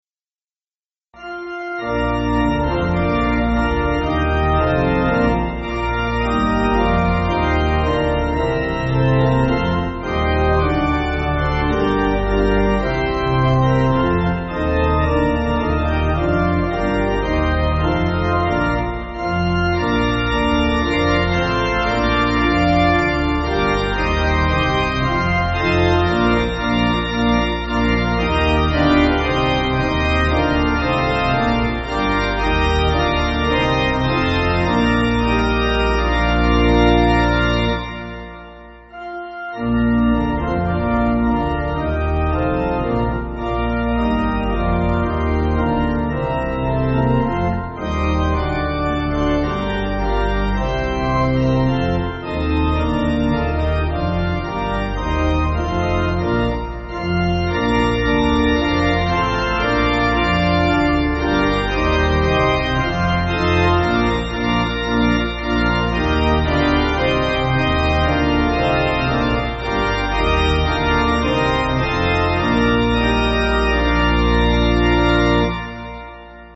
(No fermatas)